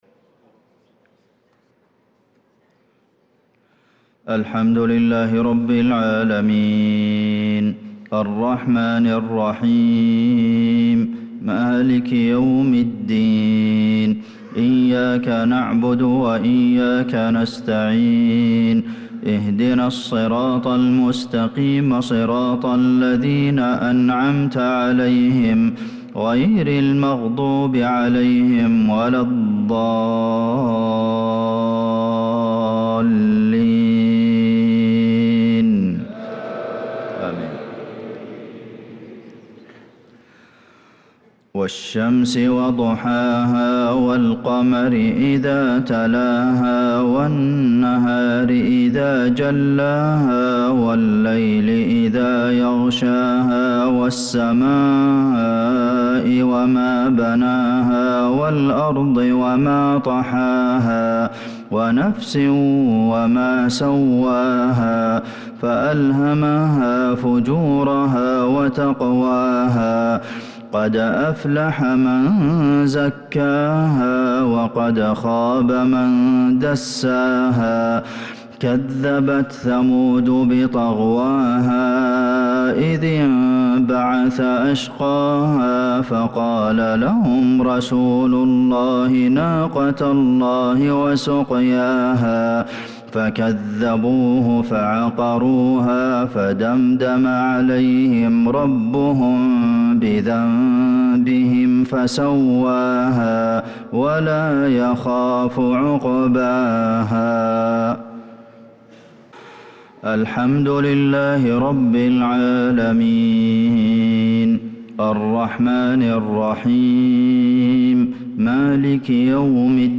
صلاة المغرب للقارئ عبدالمحسن القاسم 22 ربيع الآخر 1446 هـ
تِلَاوَات الْحَرَمَيْن .